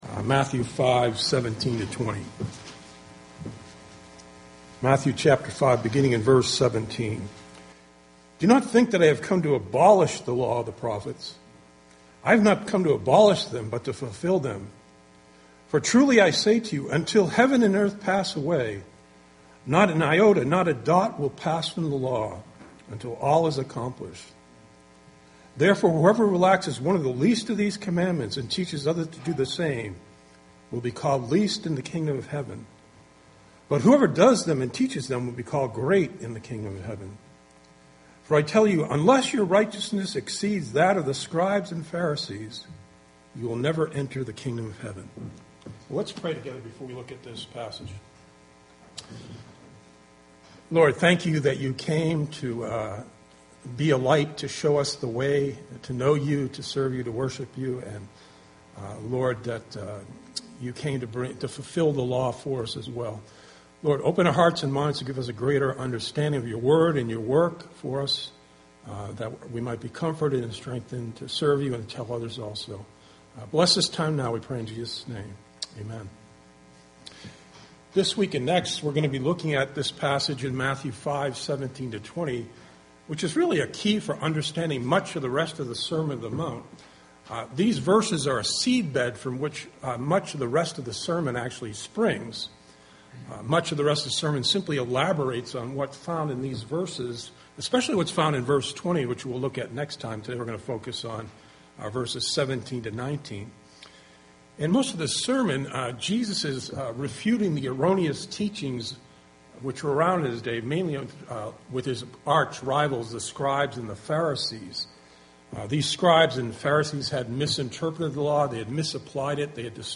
Bible Text: Matthew 5:17-20 | Preacher